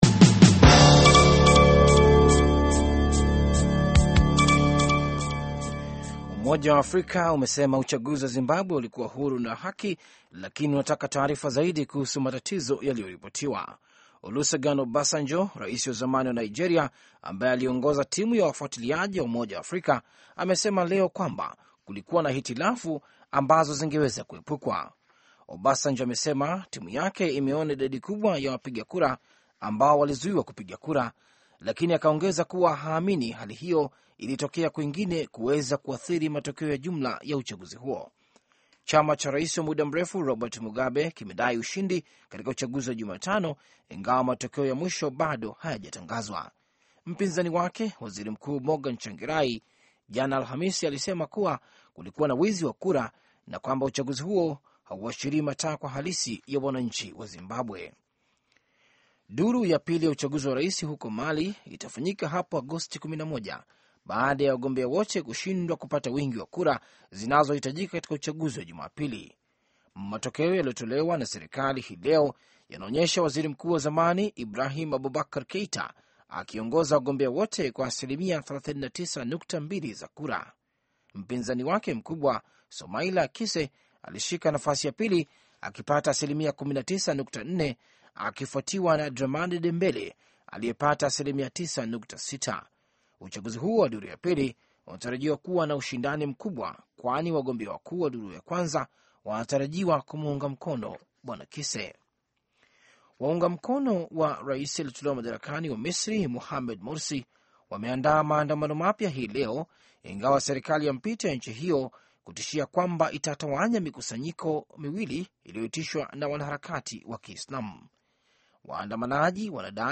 Taarifa ya Habari